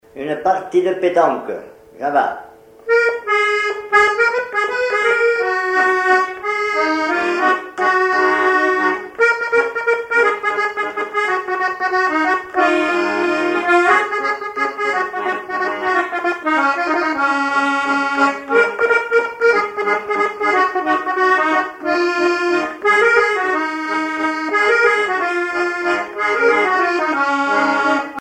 danse : java
Pièce musicale inédite